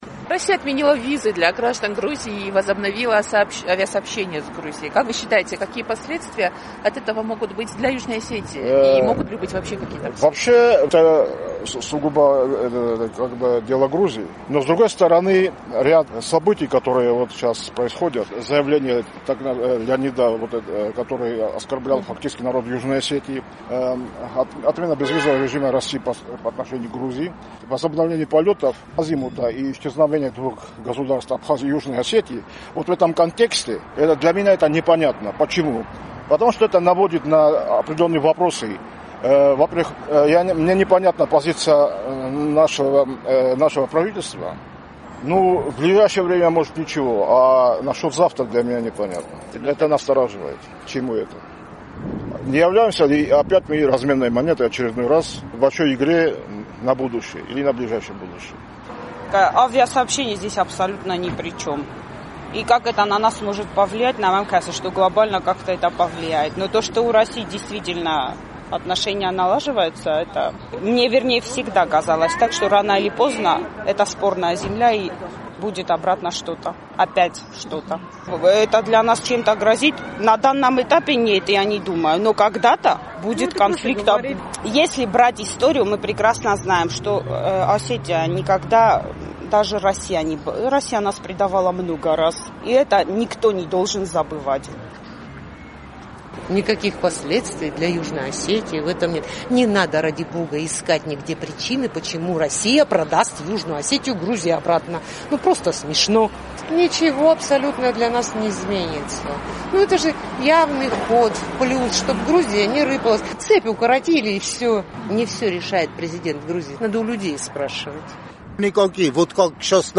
«Эхо Кавказа» узнало мнение жителей города.